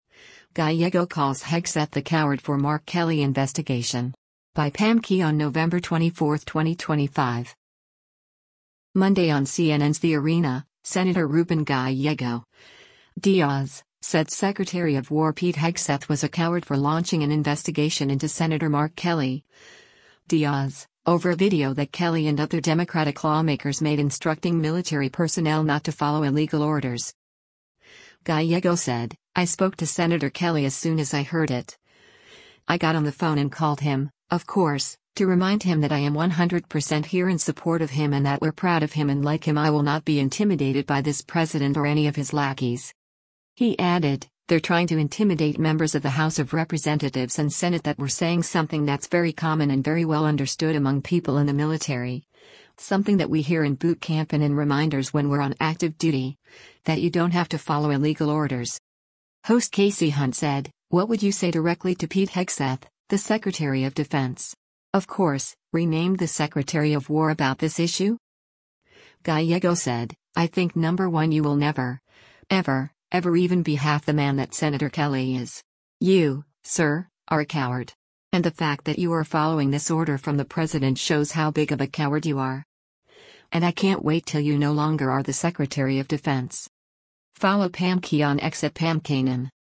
Monday on CNN’s “The Arena,” Sen. Ruben Gallego (D-AZ) said Secretary of War Pete Hegseth was a “coward” for launching an investigation into Sen. Mark Kelly (D-AZ) over a video that Kelly and other Democratic lawmakers made instructing military personnel not to follow illegal orders.